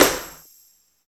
85 STMP+TAMB.wav